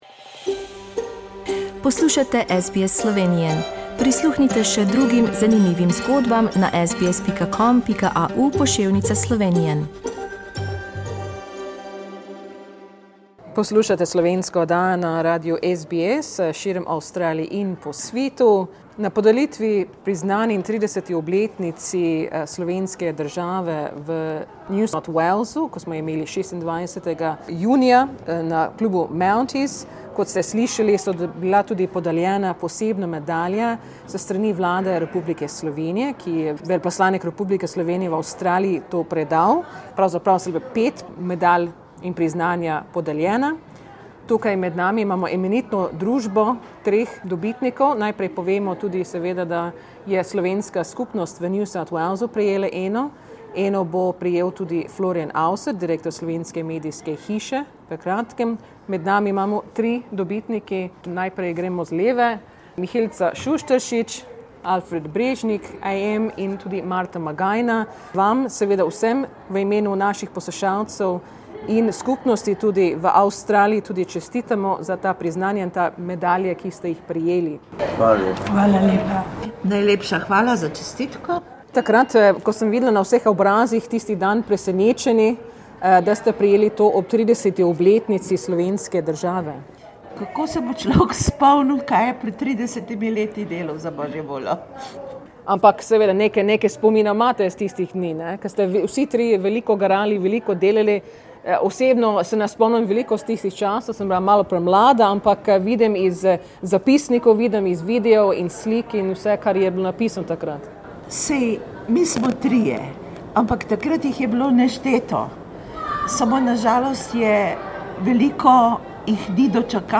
Pogovarjali smo se z njimi in jim čestitali!